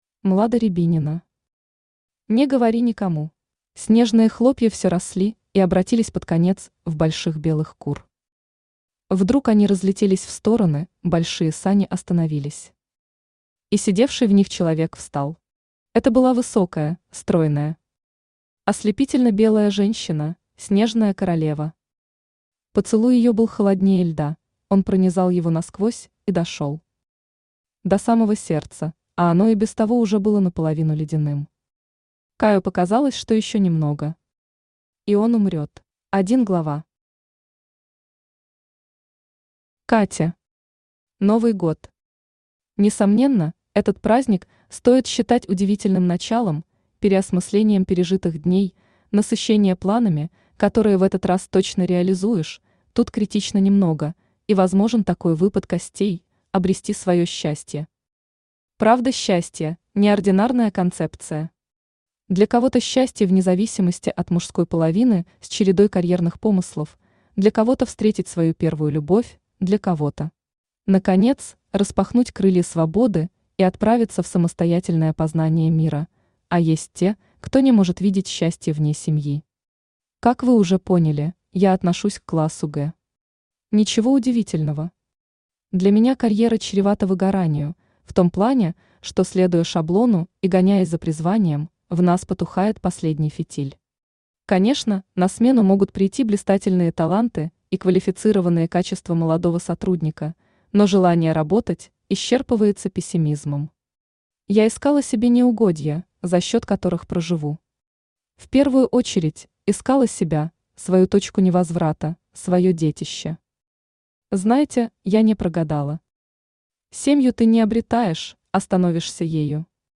Аудиокнига Не говори никому | Библиотека аудиокниг
Aудиокнига Не говори никому Автор Млада Рябинина Читает аудиокнигу Авточтец ЛитРес.